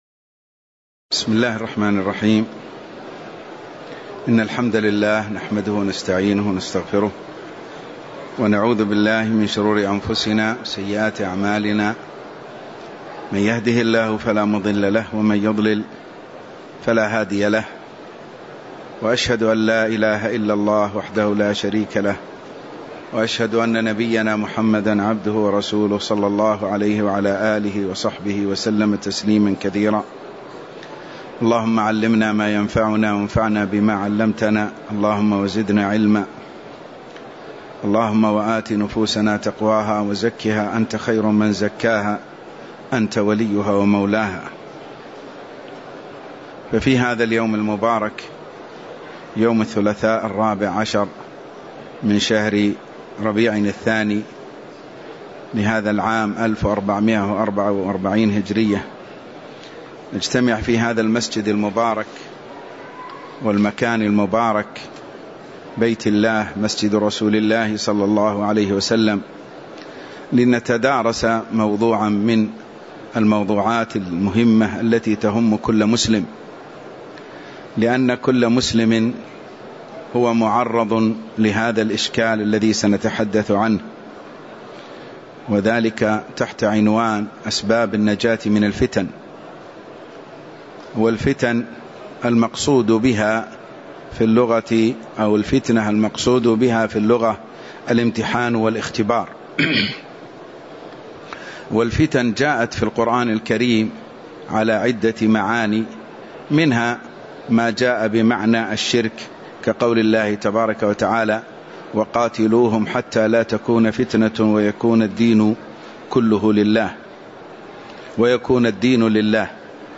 تاريخ النشر ١٤ ربيع الثاني ١٤٤٤ هـ المكان: المسجد النبوي الشيخ